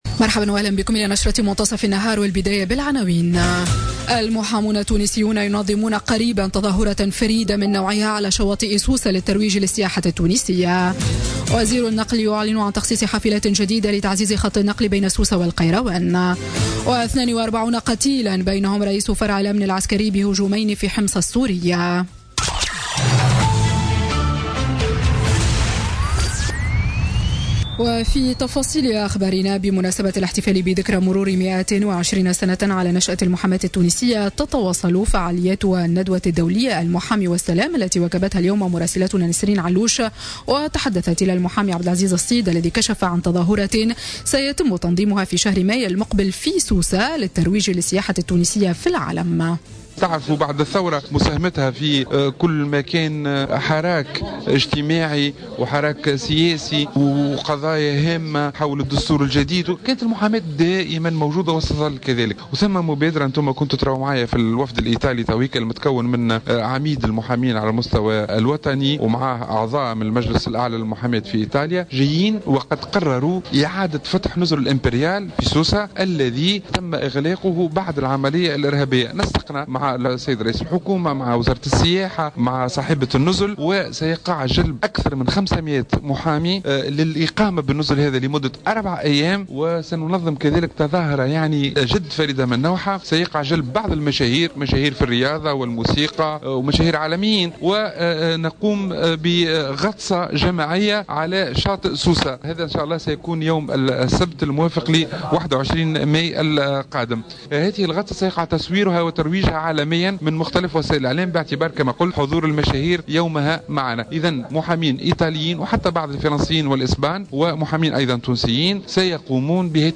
نشرة أخبار منتصف النهار ليوم السبت 25 فيفري 2017